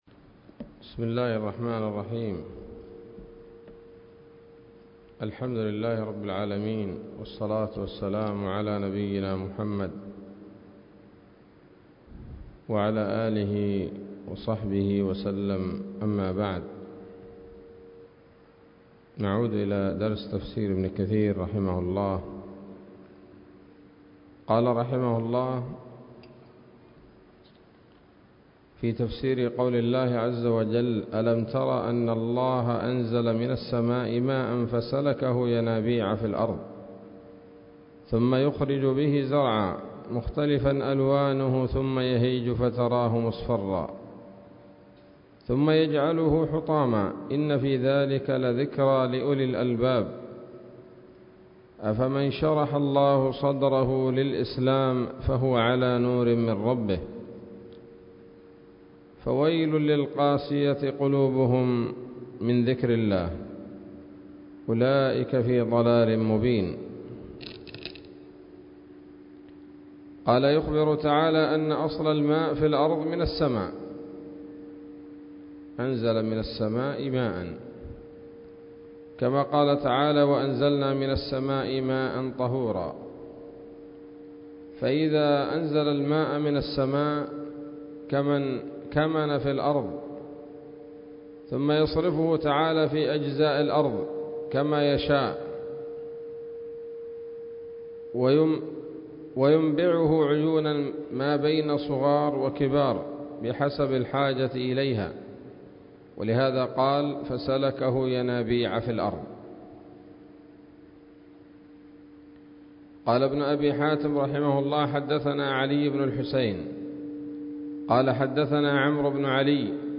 الدرس السادس من سورة الزمر من تفسير ابن كثير رحمه الله تعالى